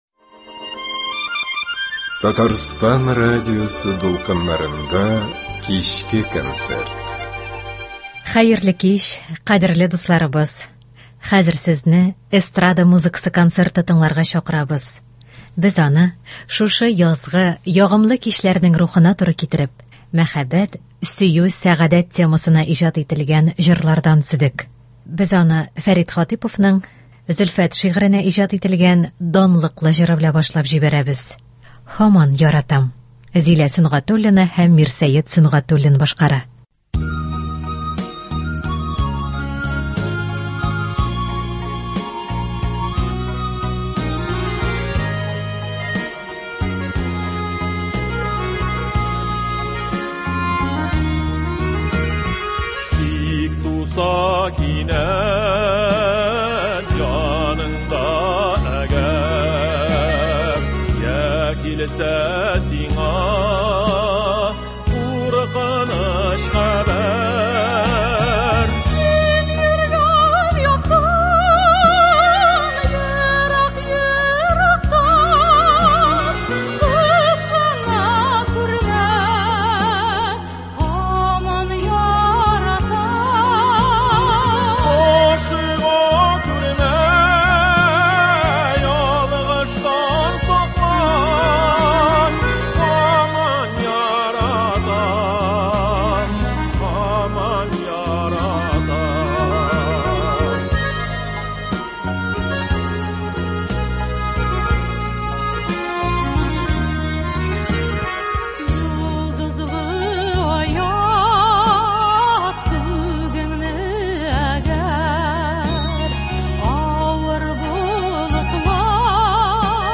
Эстрада музыкасы концерты.